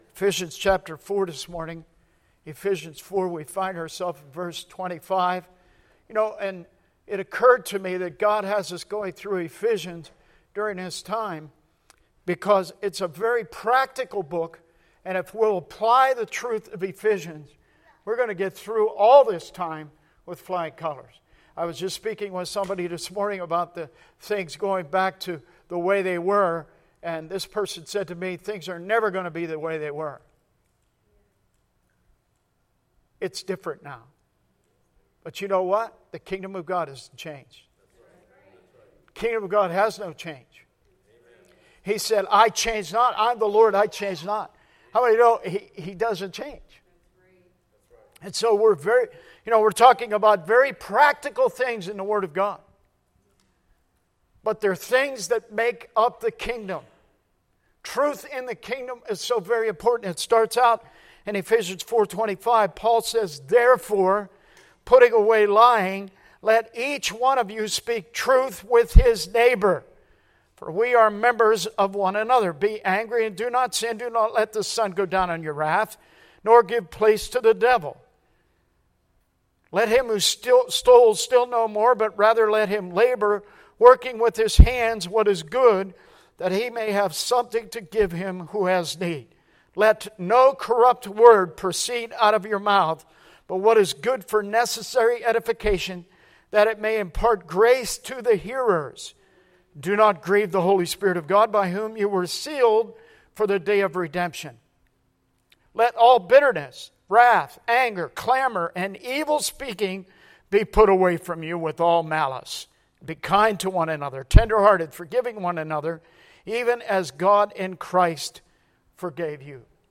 Service Type: Sunday Teaching